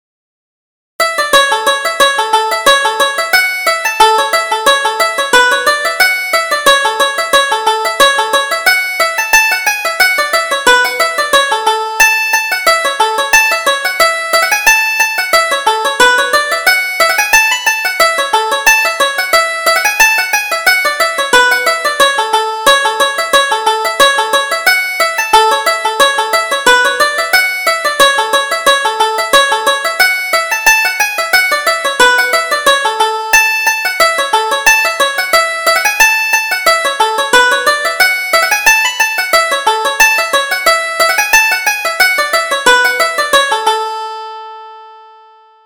Reel: The Minister's Daughter